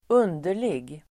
Uttal: [²'un:der_lig]